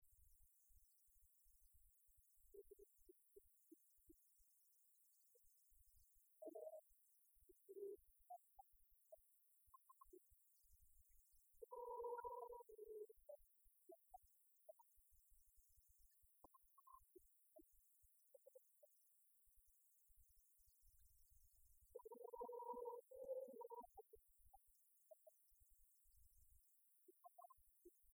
Club des retraités de Beaupréau association
berceuse
Genre strophique
Concert de la chorale des retraités